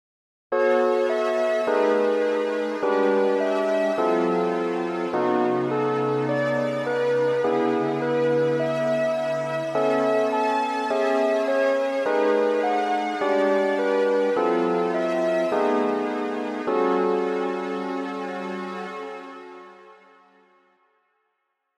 響きは「すっきり・あいまい」という感じです。
という訳で、以下の実施例は上三声に接触する位置(付加音に近い形）を織り交ぜています。